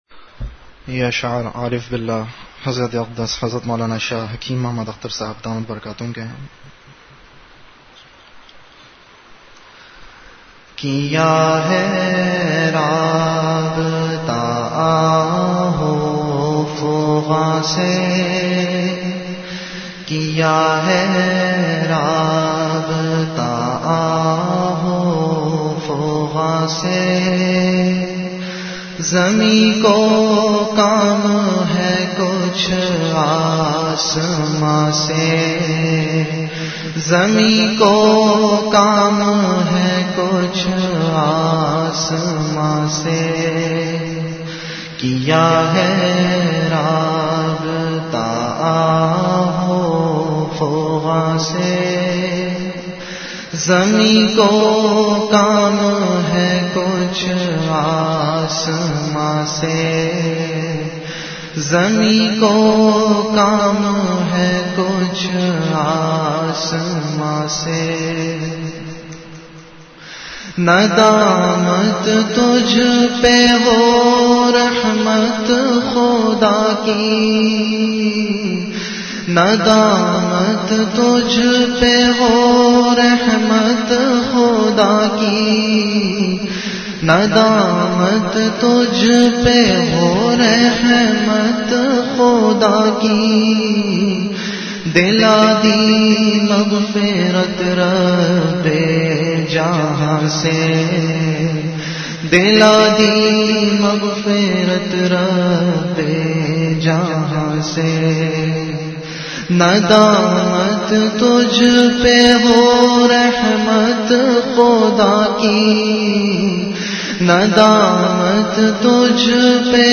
Majlis-e-Zikr · Home Mukhtasir Naseehat
Venue Home Event / Time After Isha Prayer